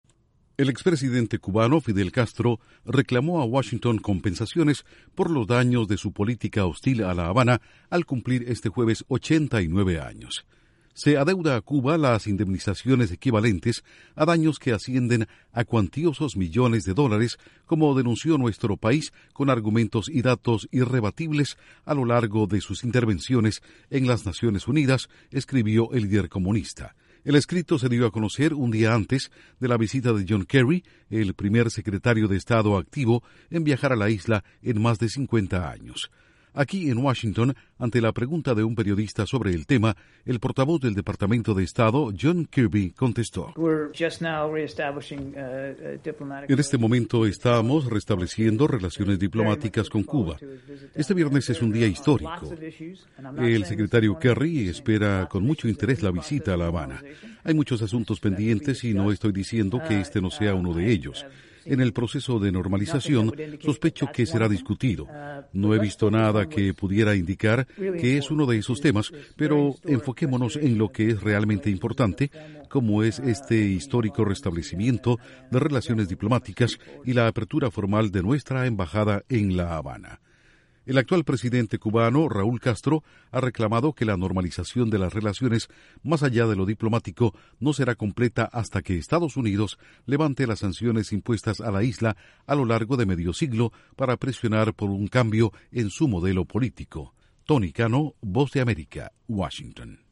EEUU: no hemos visto comentarios de Fidel Castro; estamos enfocados en el restablecimiento de relaciones diplomáticas con Cuba. Informa desde la Voz de América en Washington